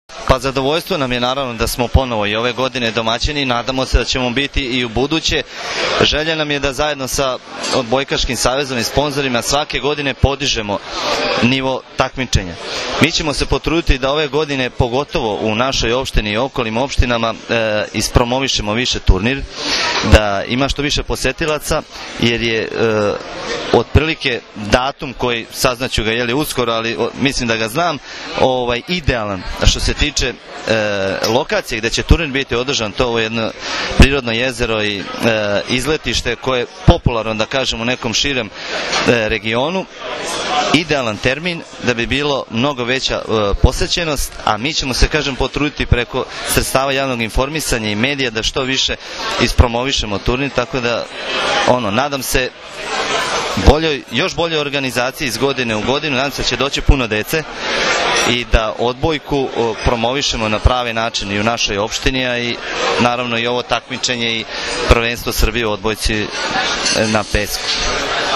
U beogradskom restoranu „Dijagonala” danas je svečano najavljen VI „Vip Beach Masters 2013.“ – Prvenstvo Srbije u odbojci na pesku, kao i Vip Beach Volley liga, u prisustvu uglednih gostiju, predstavnika gradova domaćina, odbojkašica, odbojkaša i predstavnika medija.
IZJAVA OGNJENA MARKOVIĆA, PREDSEDNIKA OPŠTINE BAČ